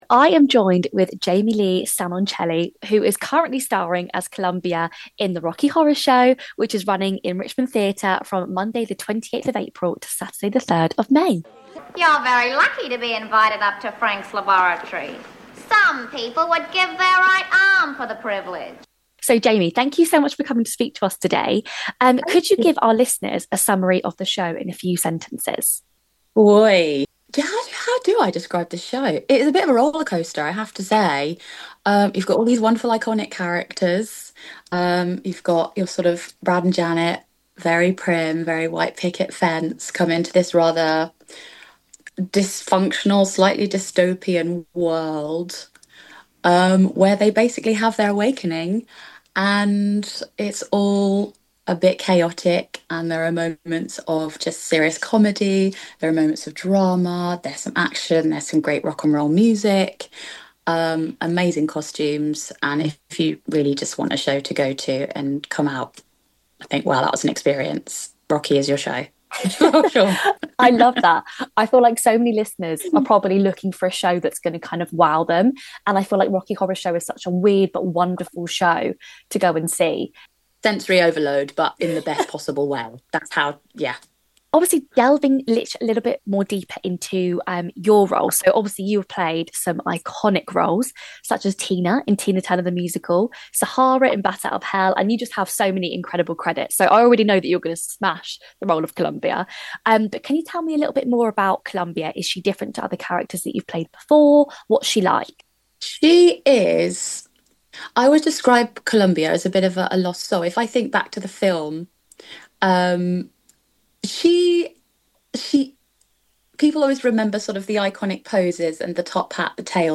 chats to actor